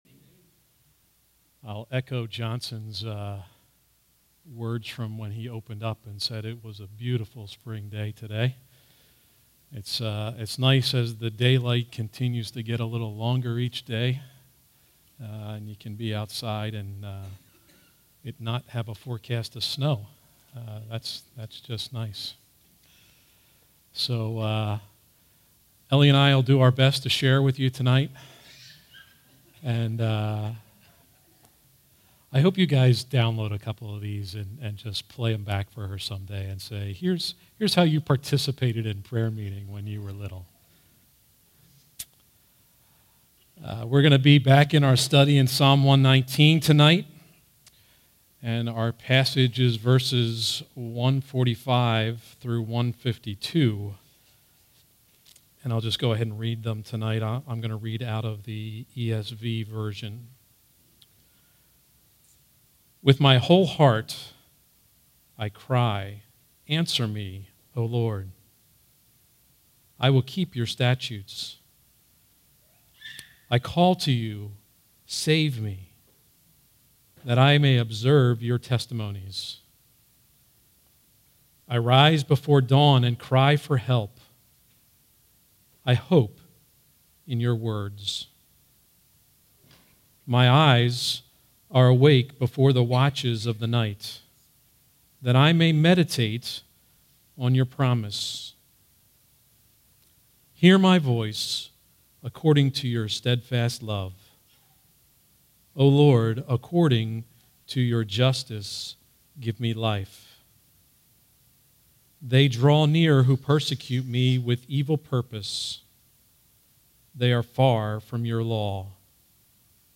All Sermons Psalm 119:145-152